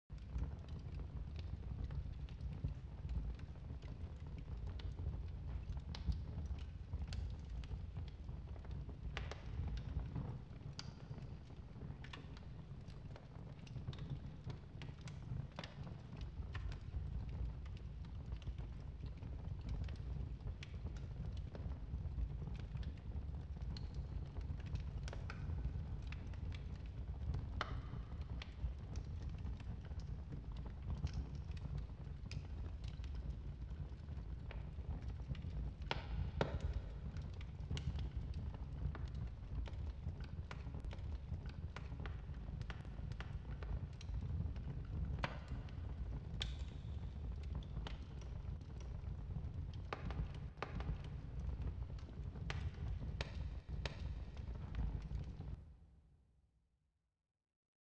FireplaceSoundBG.wav